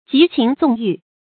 極情縱欲 注音： ㄐㄧˊ ㄑㄧㄥˊ ㄗㄨㄙˋ ㄧㄩˋ 讀音讀法： 意思解釋： 竭力滿足自己的情感和貪欲而不加節制。